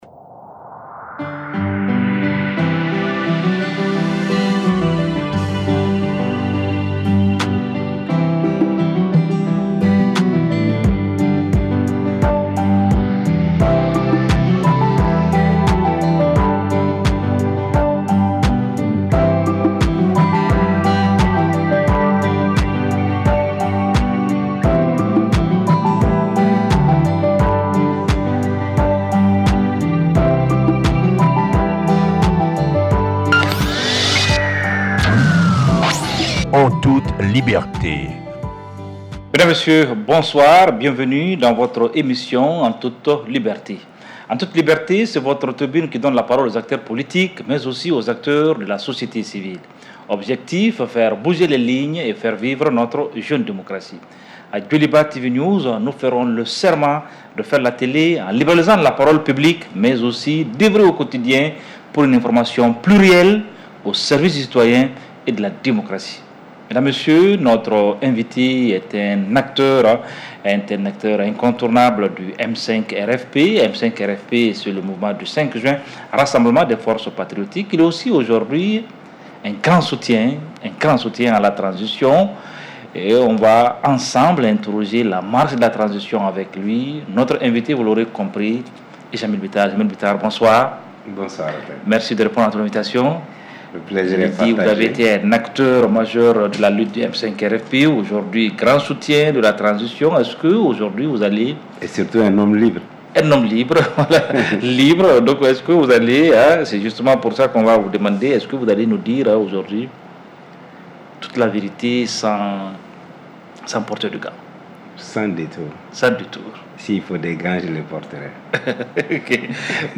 émission de débat politique